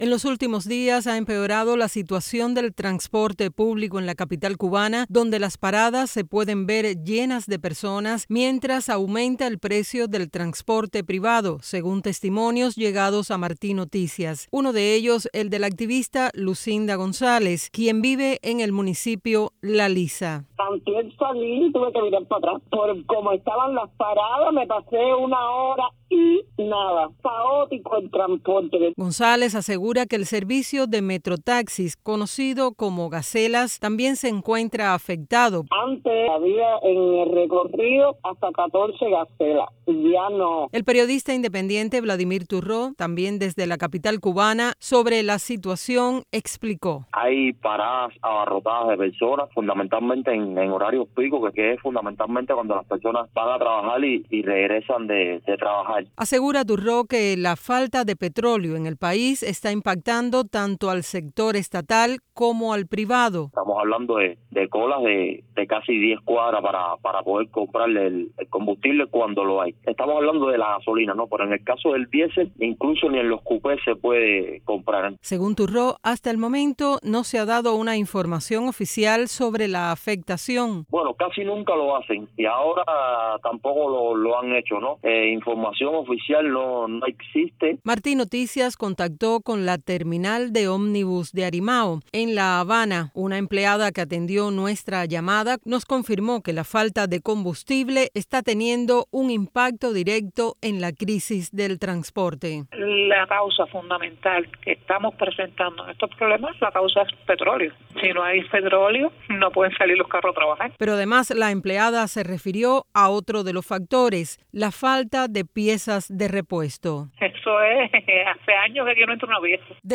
Precisamente desde la capital cubana, varios entrevistados por Martí Noticias constatan un empeoramiento de la situación del transporte público, que se ve reflejado en las paradas abarrotadas de personas y el aumento del precio del transporte privado.